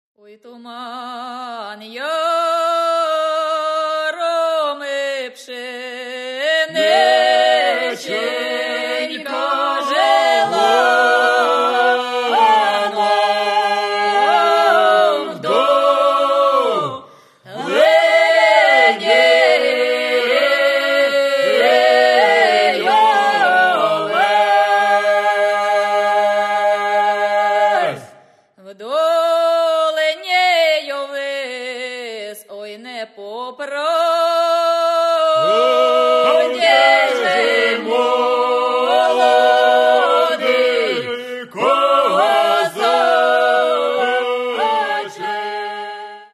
лірична